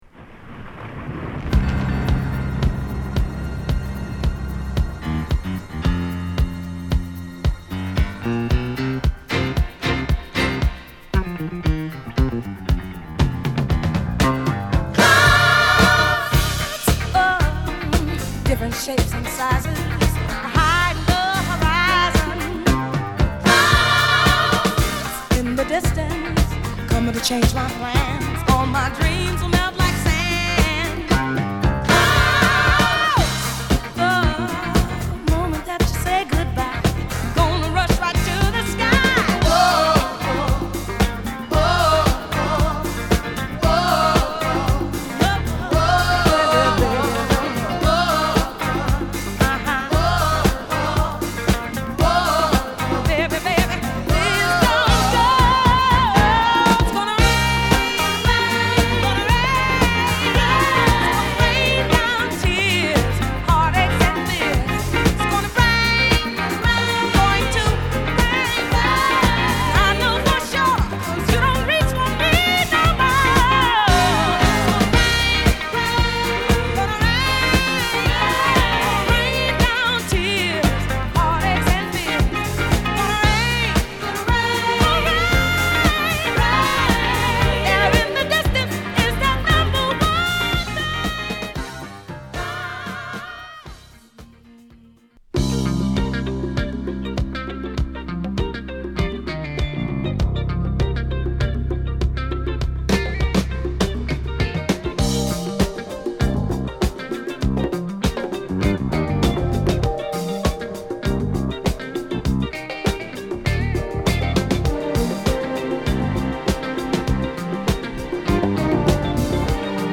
中盤には固くタイトなドラムブレイクも潜む